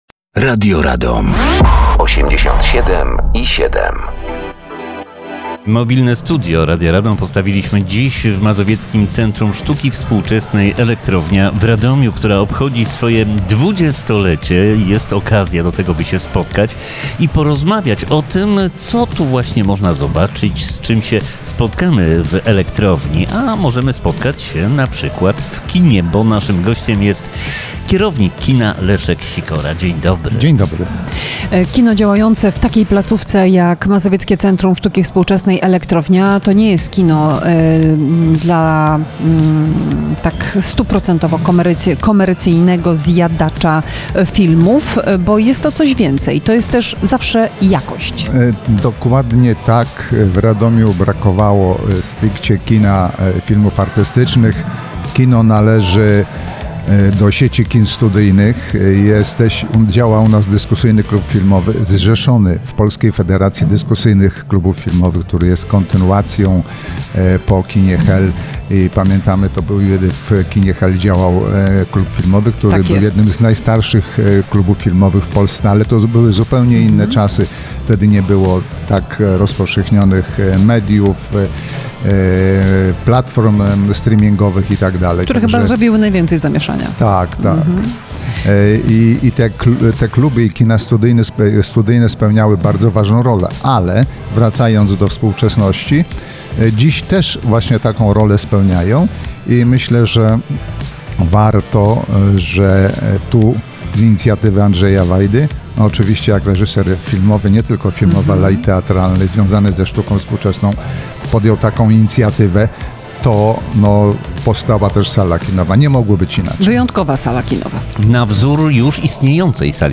Mobilne Studio Radia Radom dzisiaj w Mazowieckim Centrum Sztuki Współczesnej Elektrownia w Radomiu.